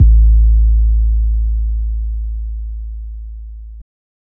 (a) tm88 808.wav